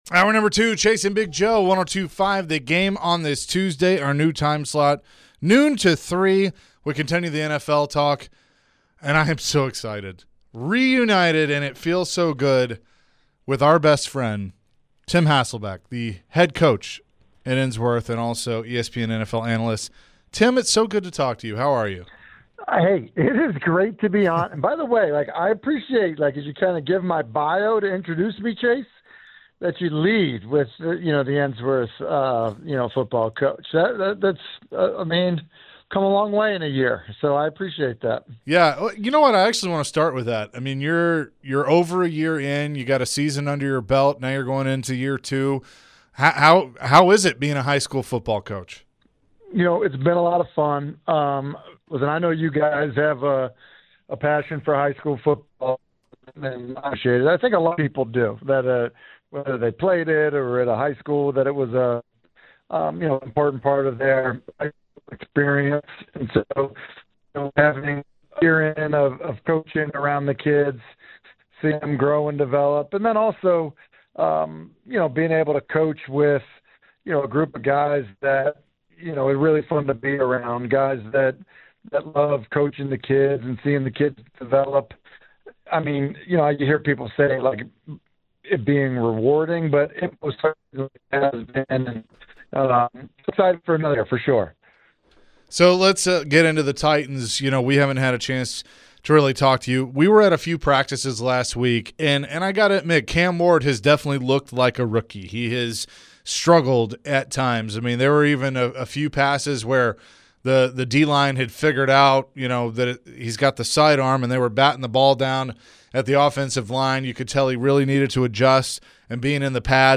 ESPN NFL Analyst/ Ensworth Tigers Head Coach Tim Hasselbeck joined the show to discuss his upcoming high school football season. Tim was also asked about Cam Ward and his upcoming rookie year.